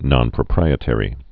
(nŏnprə-prīĭ-tĕrē)